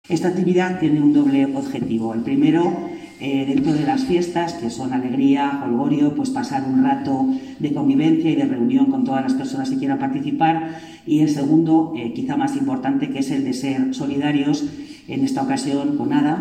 Declaraciones de la vicepresidenta de Diputación Susana Alcalde